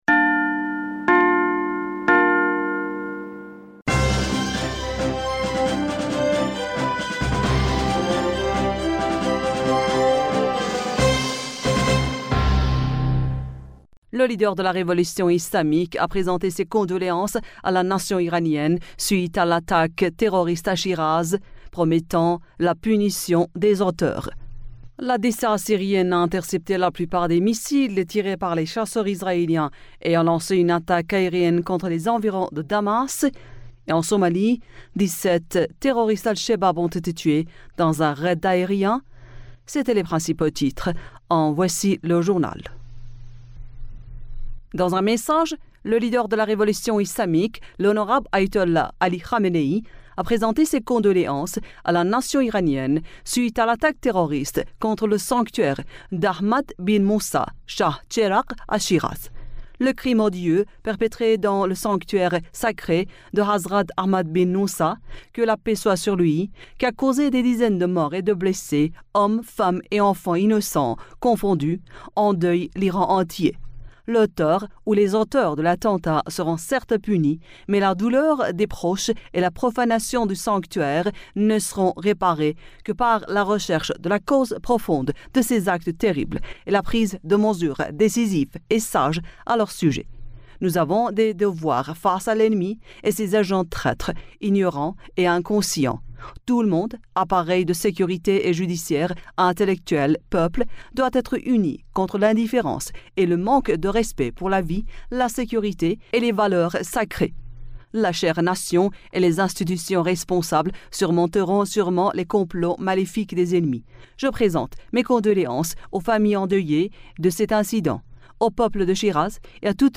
Bulletin d'information Du 27 Octobre